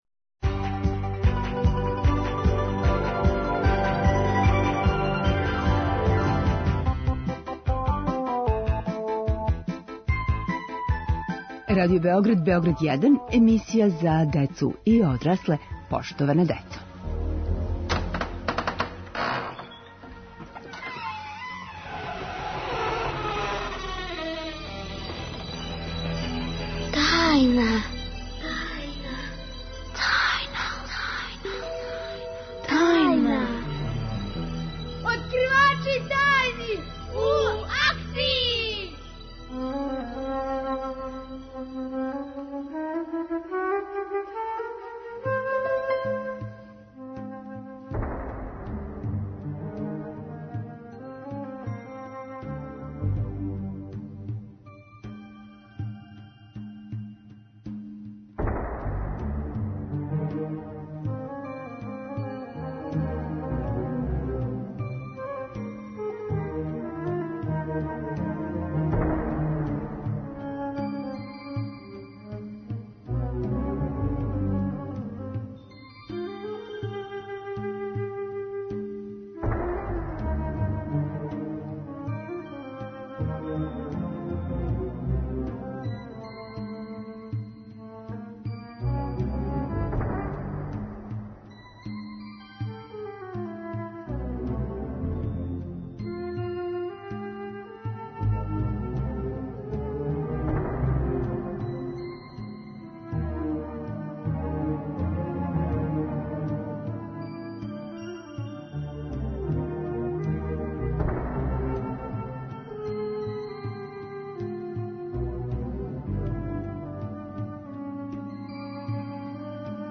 Авантуре екипе с КЛУПИЦЕ!? Гости су деца, маме и по који лепо васпитани четвороножац, погодан за боравак у студију.